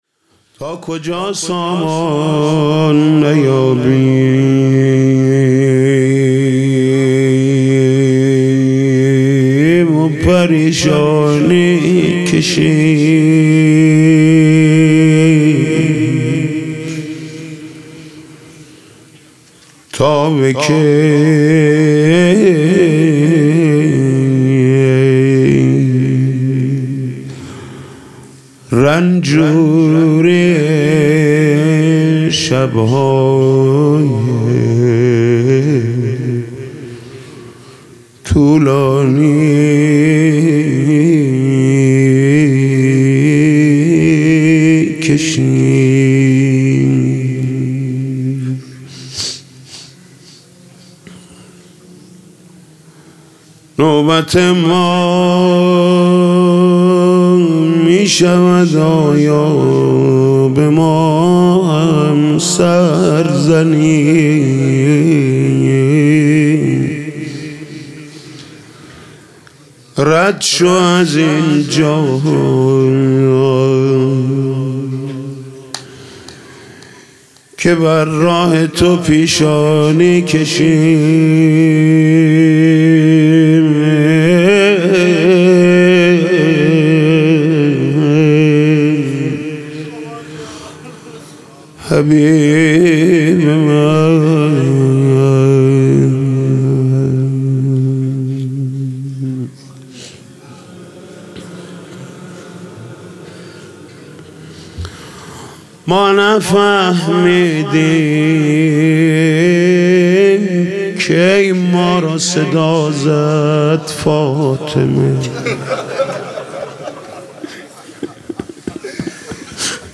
فاطمیه 96 شب سوم مناجات محمود کریمی